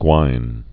(gwīn)